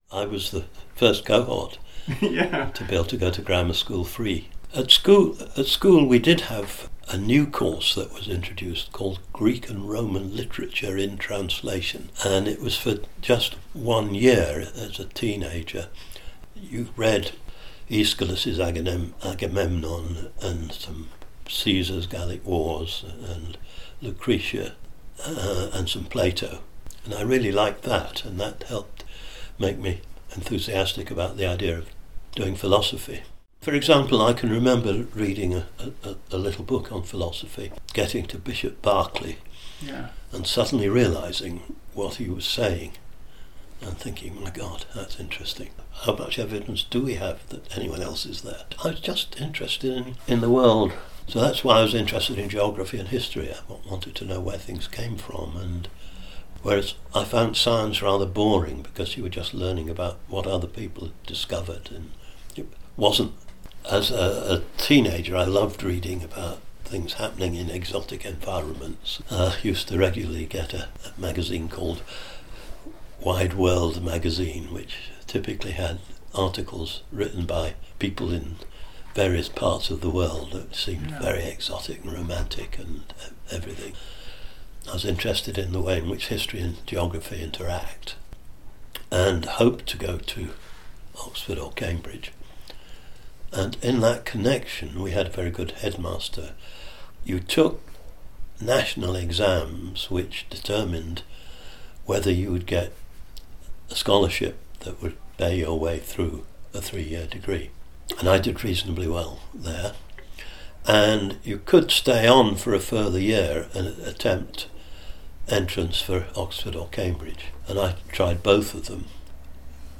I am thrilled to share with you some excerpts and ideas from my lively conversation with the one of Psychology’s bad asses, Alan Baddeley!